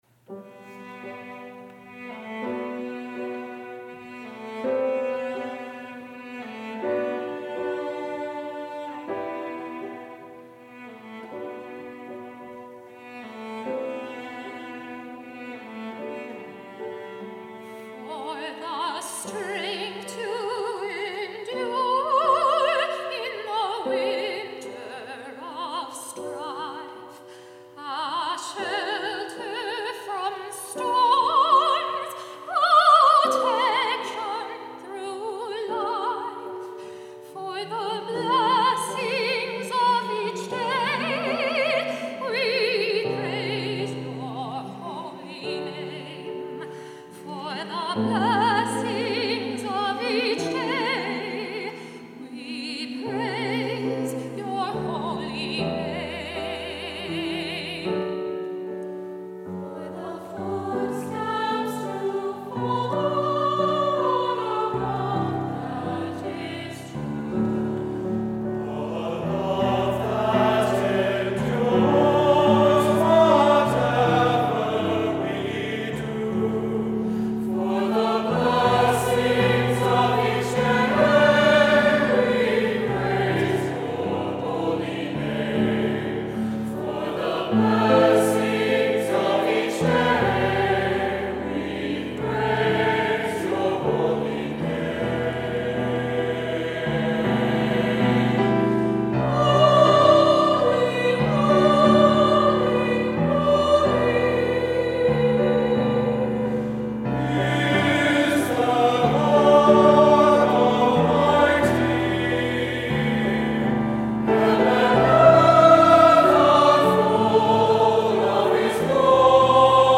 Voicing: "SATB","Solo"